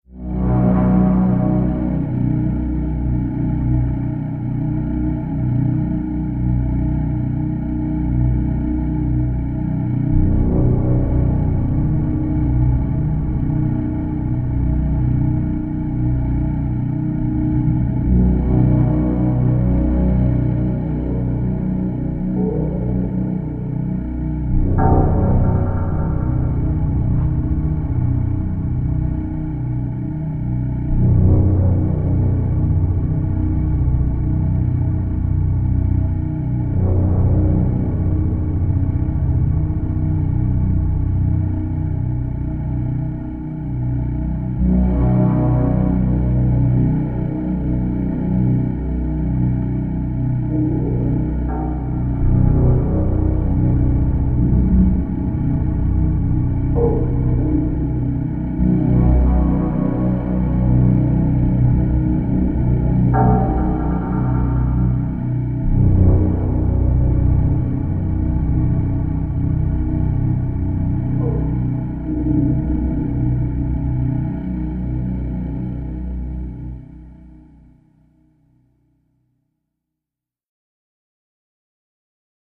Medieval Cave Ambience Cave, Medieval